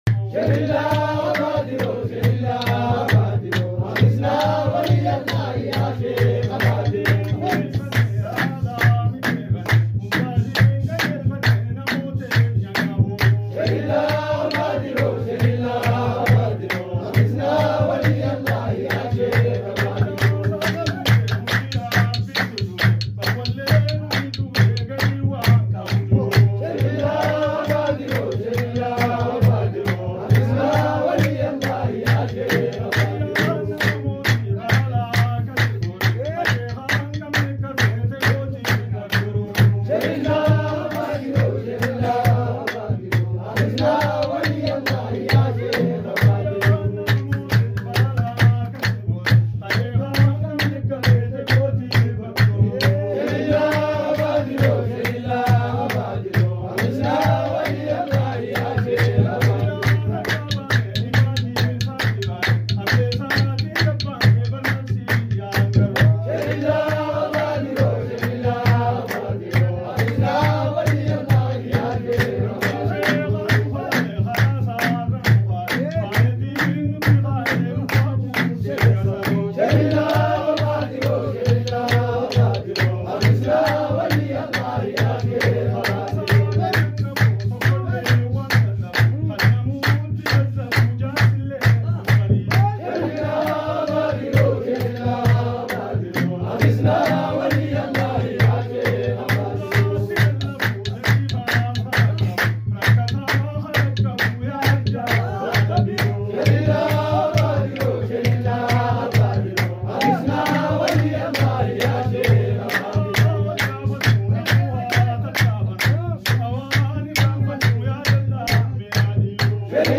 Sacred Harari music